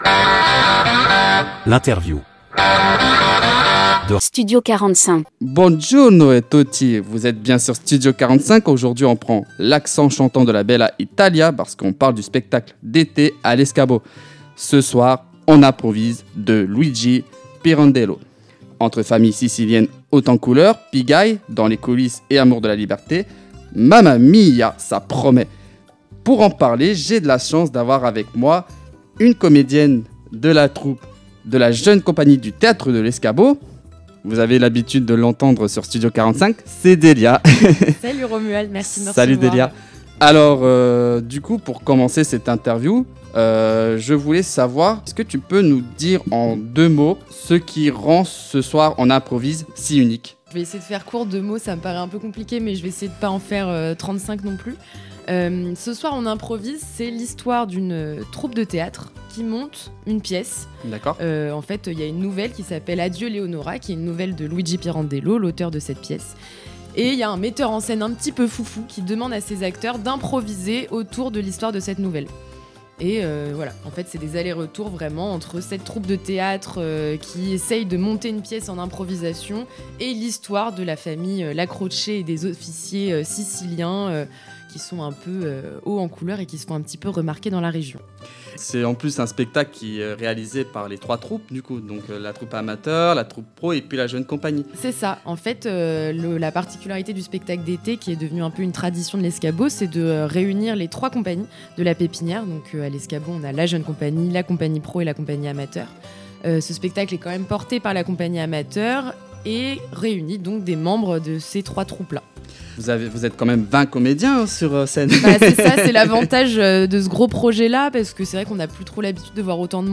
Interview de Studio 45 - Le spectacle d'été du Théâtre de l'Escabeau